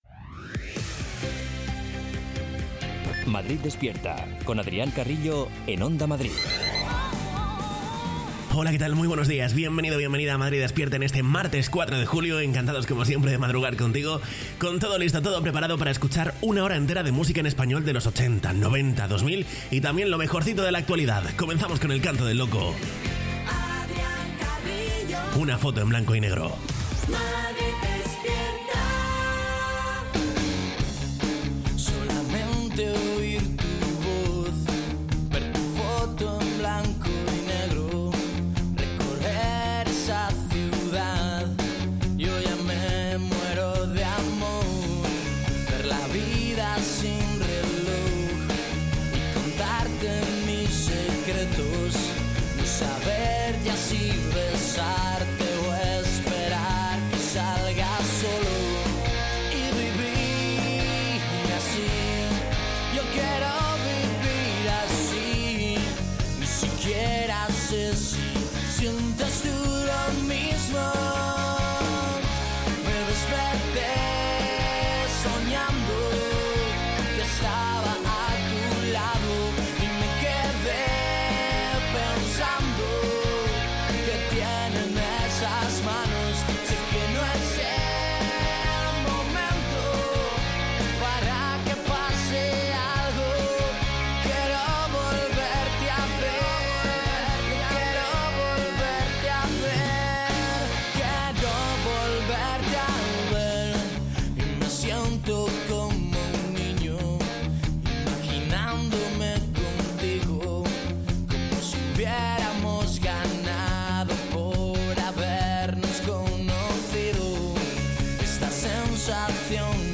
Morning show
con la mejor música y la información útil para afrontar el día